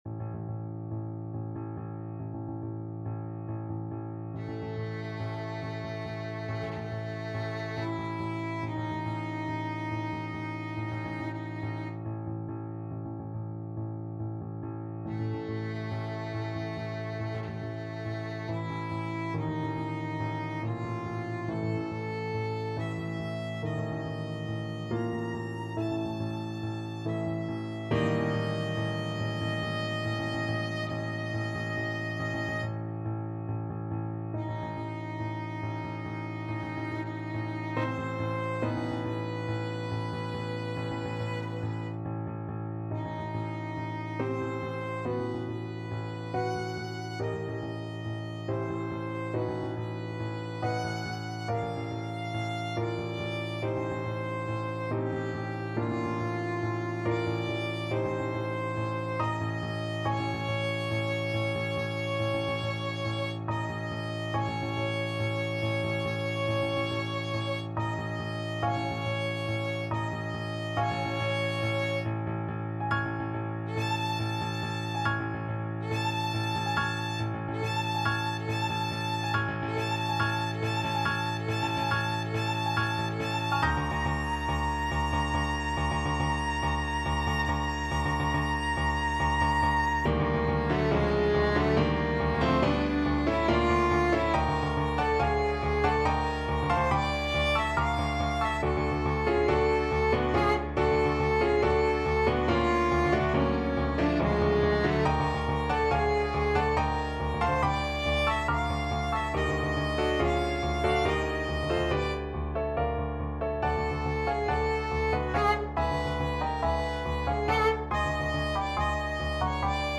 Allegro = 140 (View more music marked Allegro)
5/4 (View more 5/4 Music)
Classical (View more Classical Violin Music)